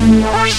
tekTTE63029acid-A.wav